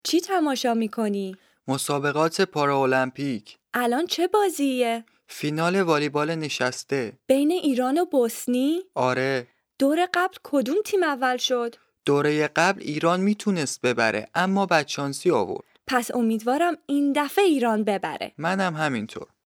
DIALOGUE 1
Dialogue1-lesson39-Farsi.mp3